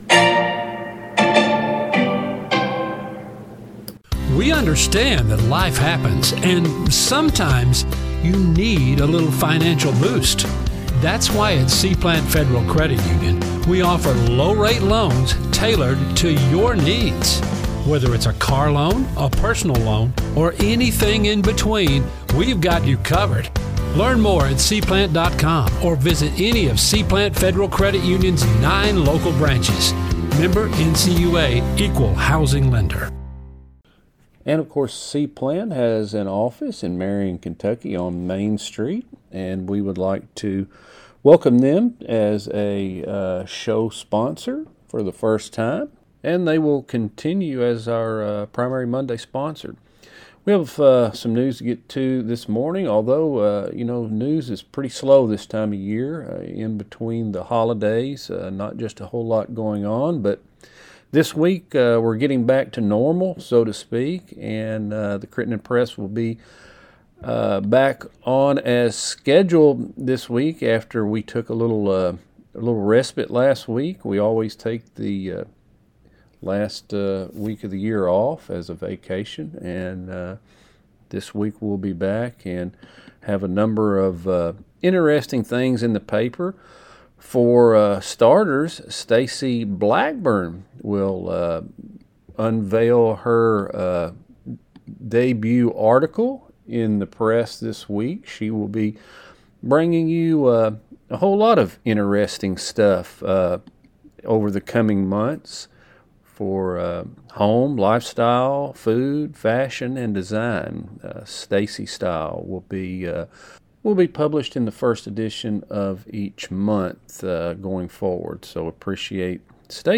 MONDAY NEWScast by C-Plant Credit Union
MONDAY NEWScast by C-Plant Credit Union LISTEN NOW News | Sports | Interviews By Crittenden Press Online at January 05, 2026 Email This BlogThis!